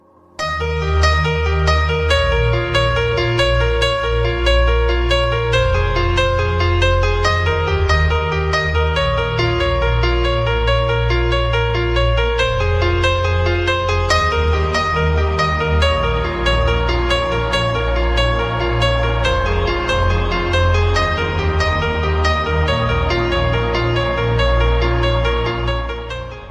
Category Alarm Tone